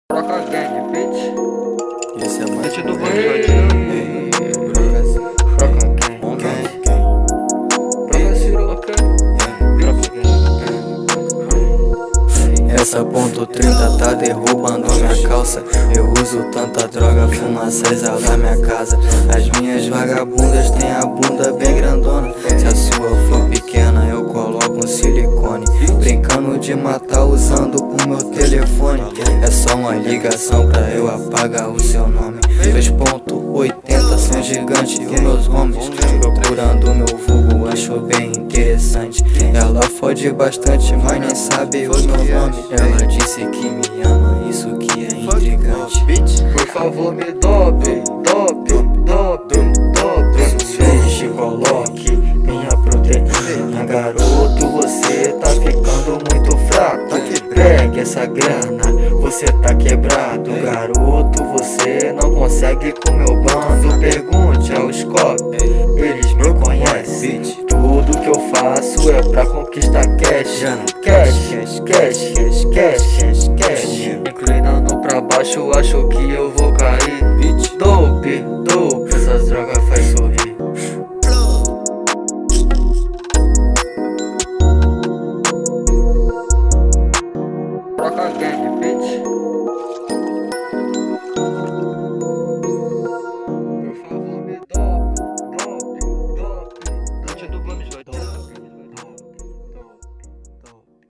2025-02-06 22:22:26 Gênero: Rock Views